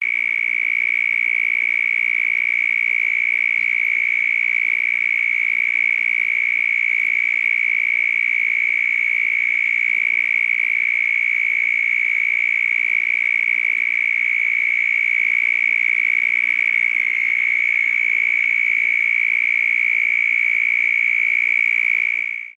Цикада
Тут вы можете прослушать онлайн и скачать бесплатно аудио запись из категории «Насекомые, земноводные».